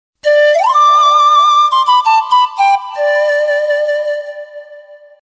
без слов
короткие
Гонг в Кишиневском Аэропорту В Молдове